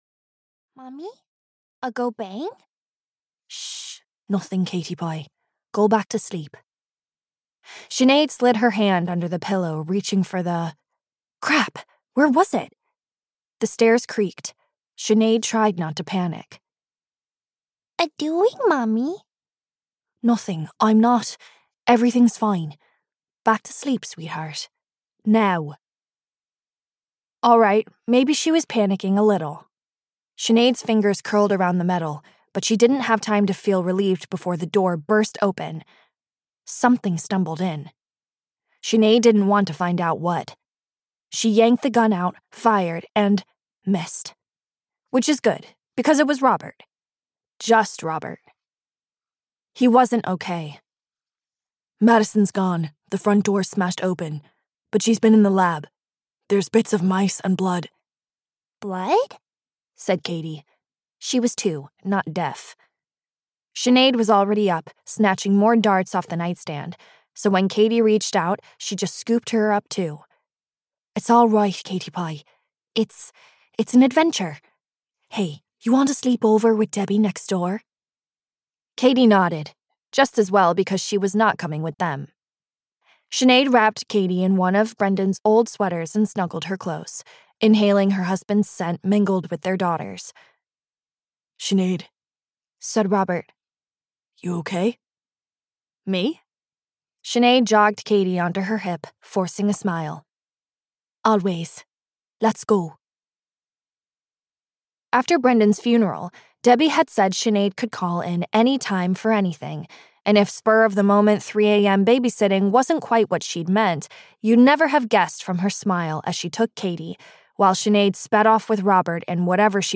Big Bad Me (EN) audiokniha
Ukázka z knihy